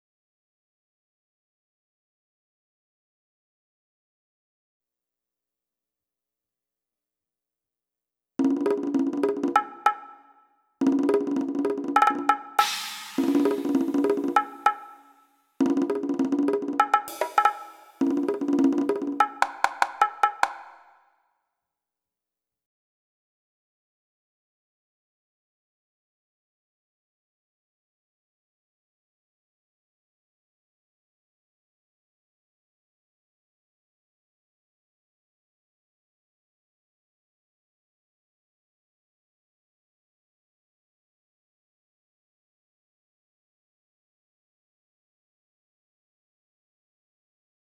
bueno_100_perc7.wav